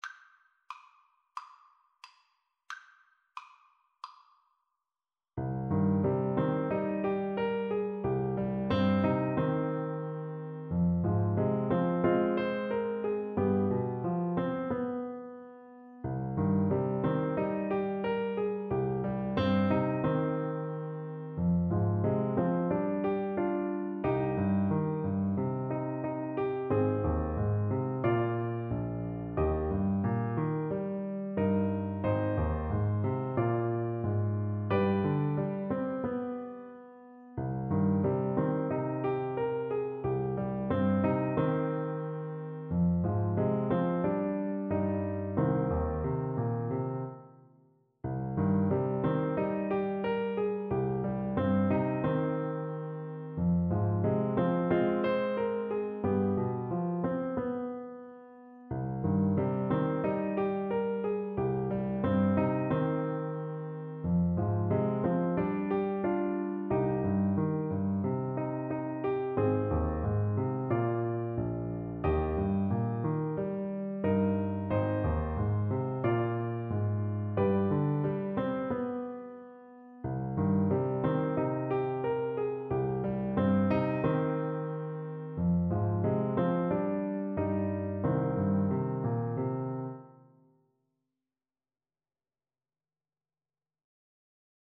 Andante = c. 90